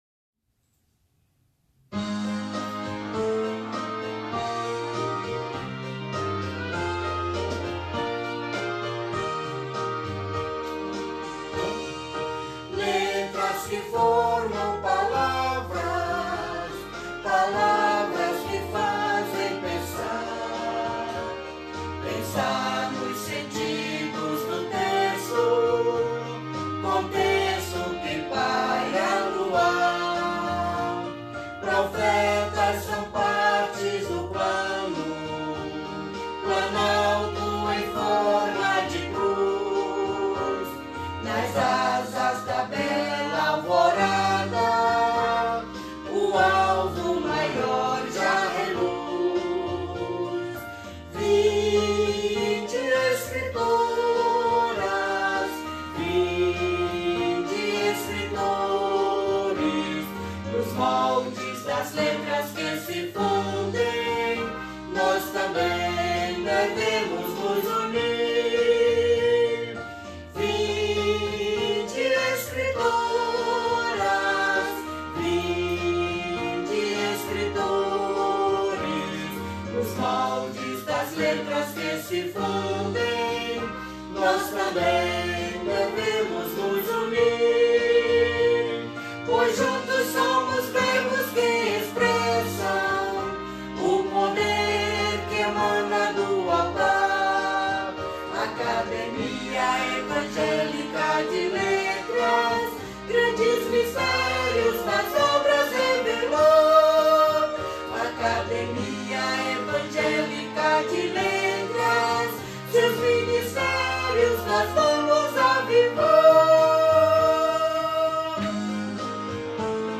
Hinos